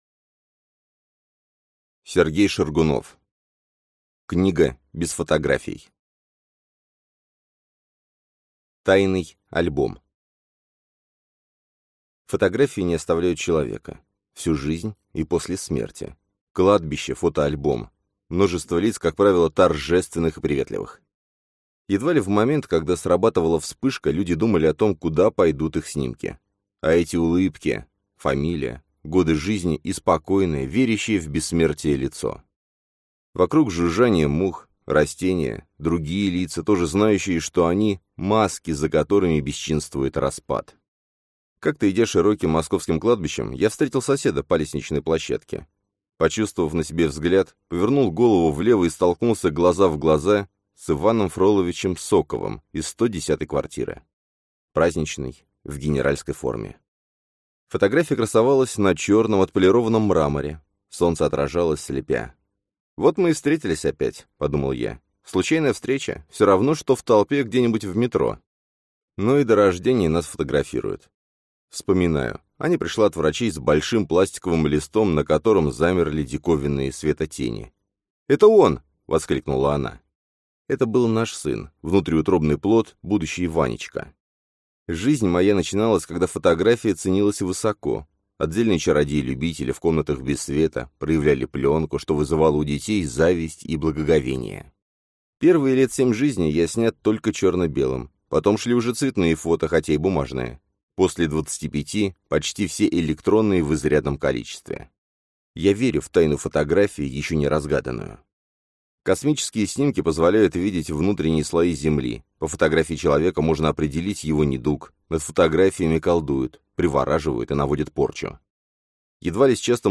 Аудиокнига Книга без фотографий | Библиотека аудиокниг